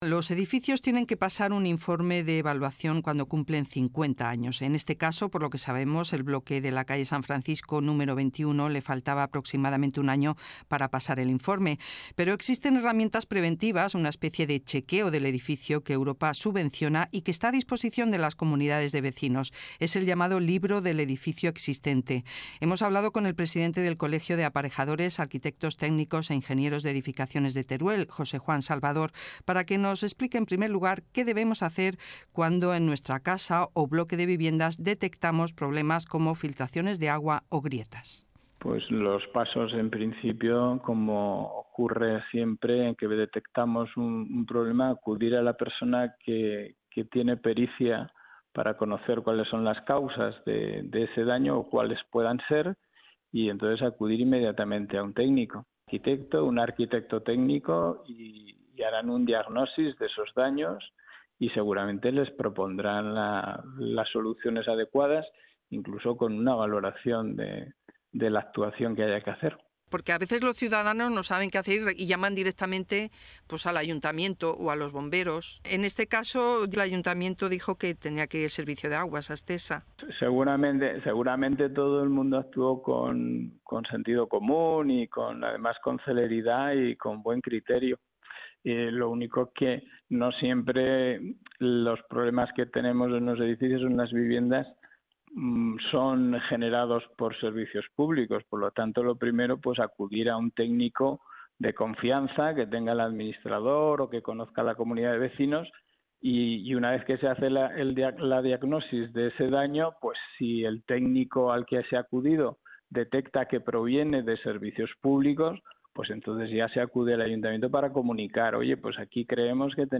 Compartimos otra entrevista, ahora en